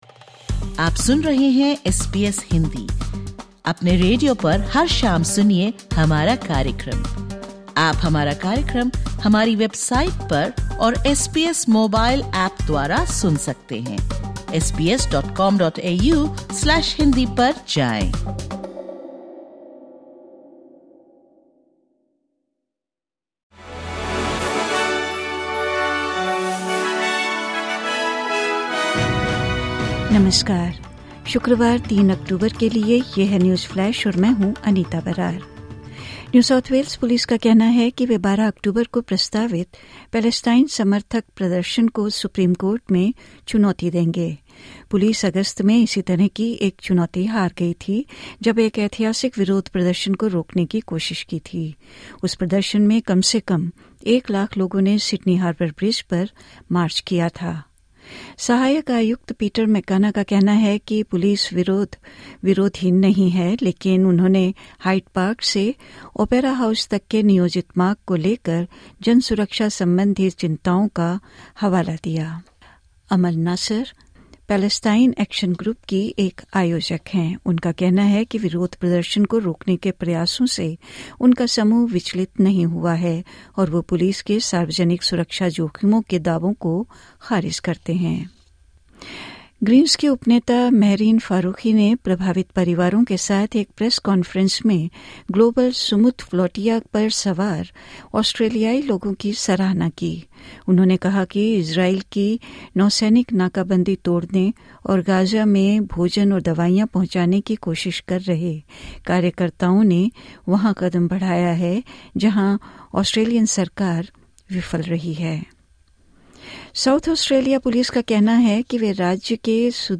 ऑस्ट्रेलिया और भारत से 03/10/2025 के प्रमुख समाचार हिंदी में सुनें।